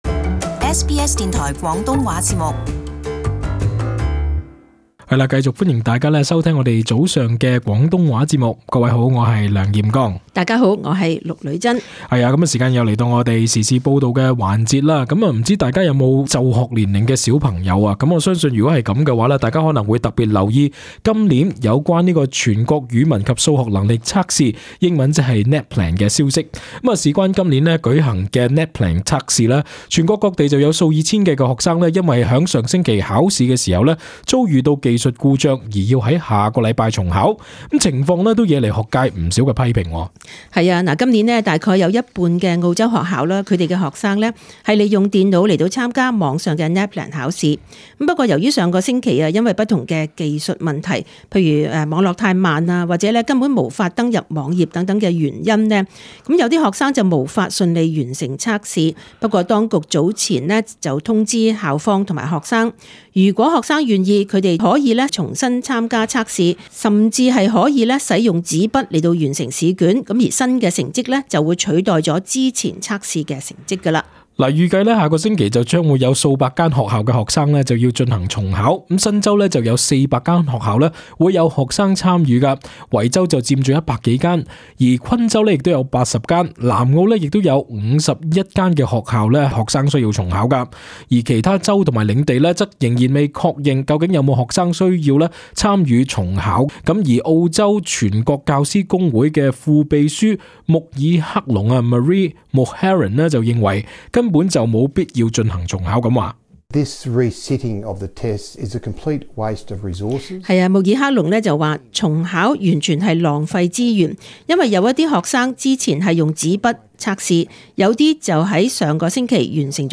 【時事報導】澳洲學界抨擊NAPLAN重考安排